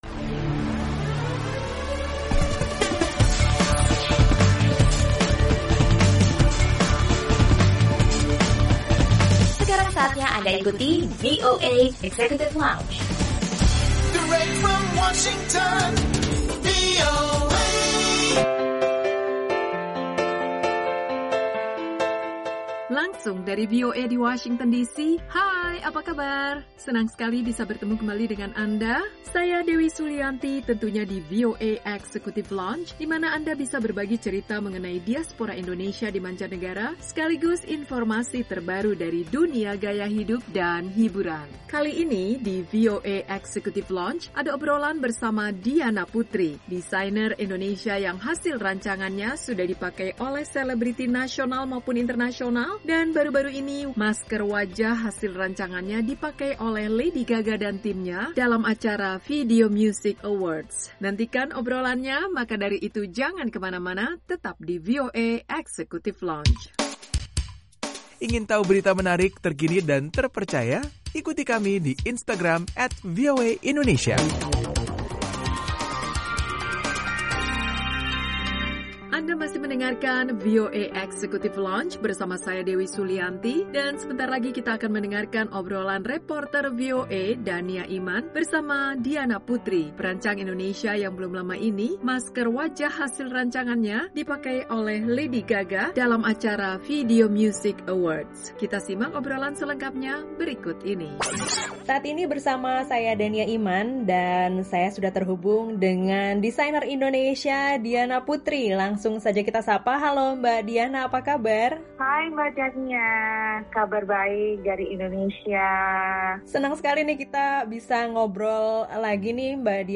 Obrolan reporter